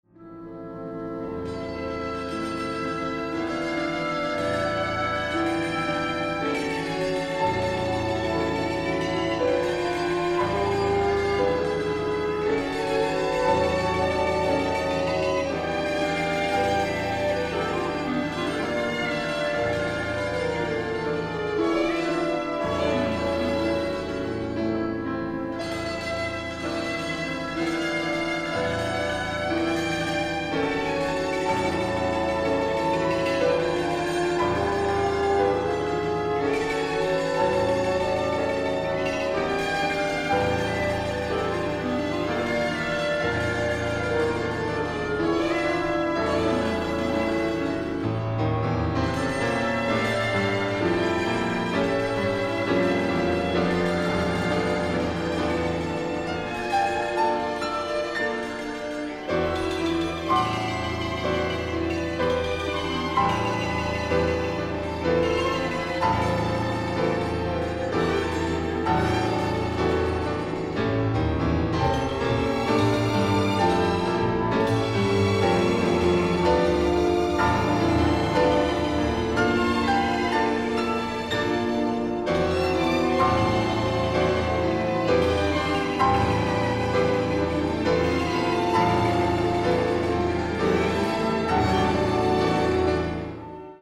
Here you can listen to recordings of the flexaton part in the second movement with Musical saw and with Flexatone.
2. with Flexatone
The sound of a Flexatone has always the chattering of the little mallets, which sounds like a doorbell and disturbs the lyrical character of this movement
Flexaton-Khachaturian.mp3